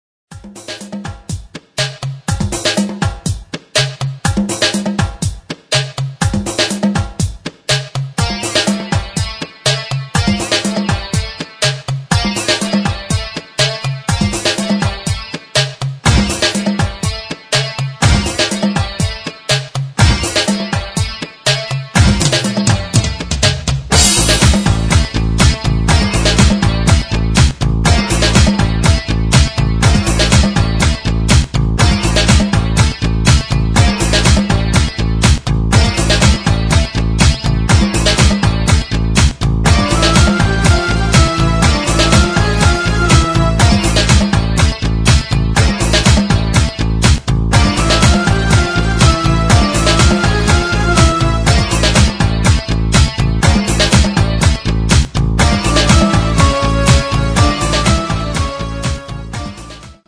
saxofoon, klarinet, gitaar en piano
tribal rhythms and ethnic instruments